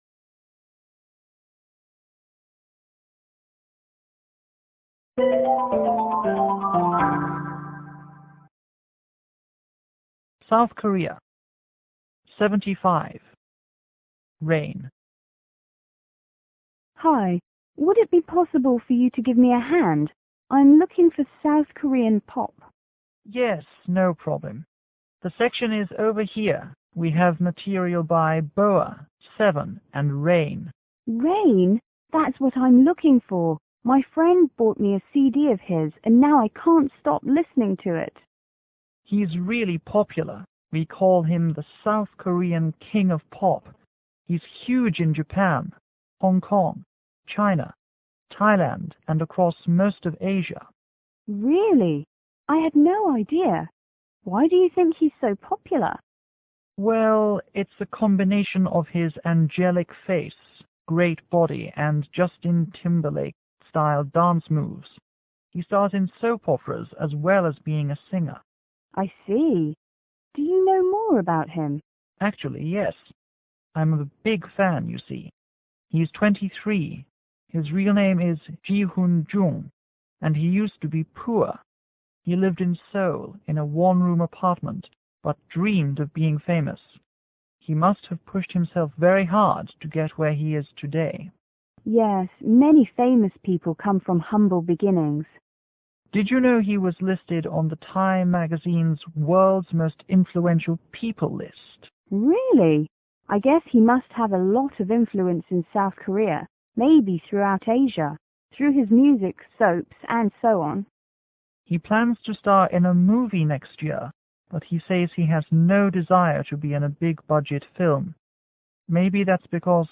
S: Shopper     M: Music shop owner